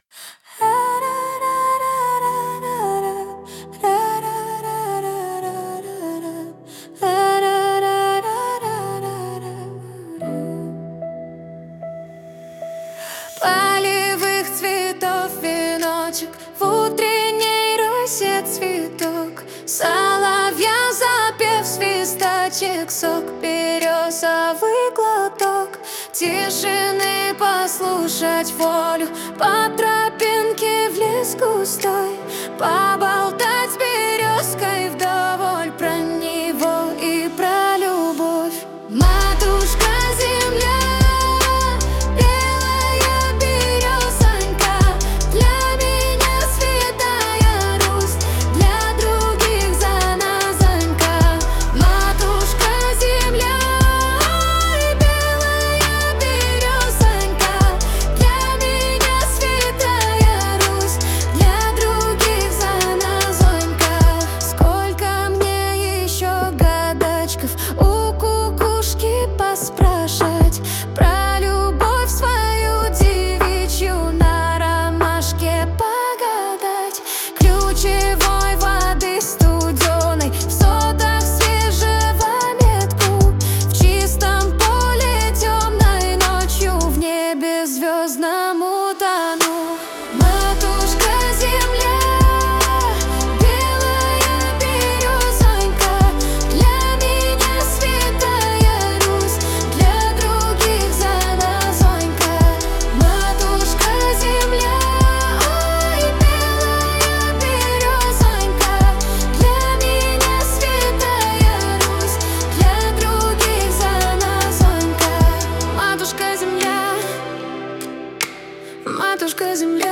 Кавер на популярную песню